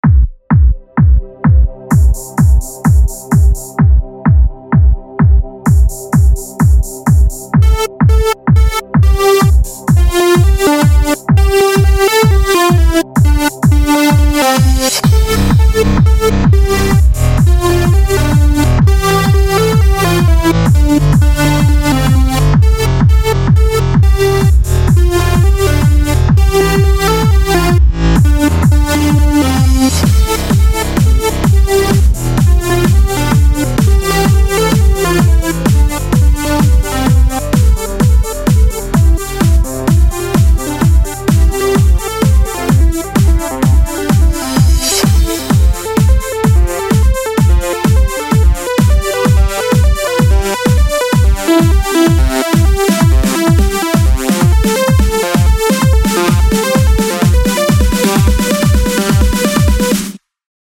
Arps und Melodie